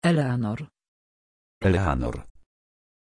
Aussprache von Éleanor
pronunciation-éleanor-pl.mp3